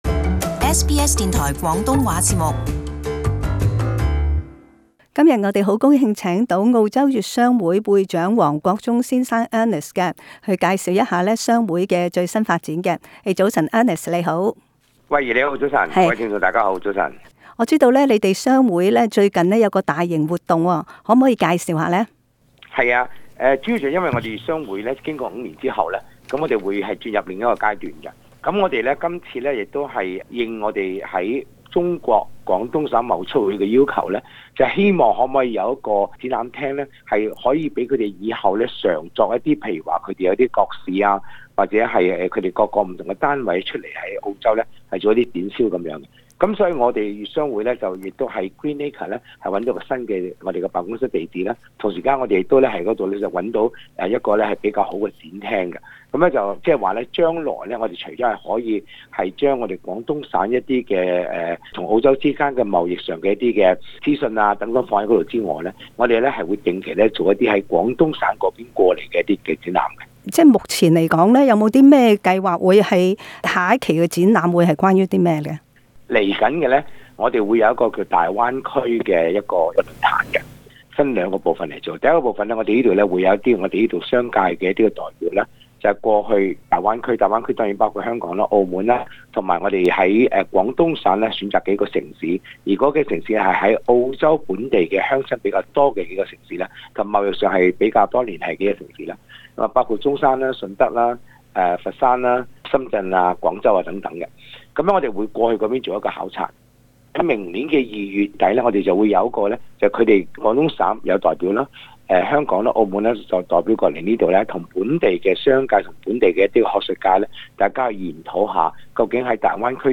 【社區專訪】澳洲粵商會新計劃